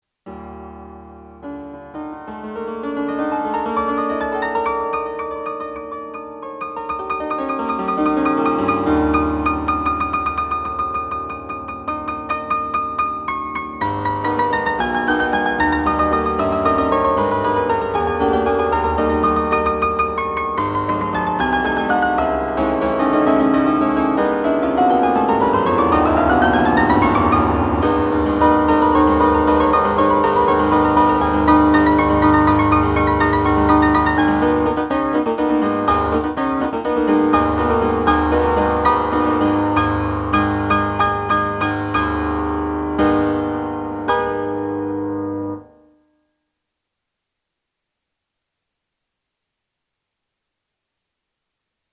無理はせず、半分くらいのスピードで弾いたものをMIDI形式で録音し、倍速にした。
前回と比べたら大分良くなったが、なんだか生気が無い気がする。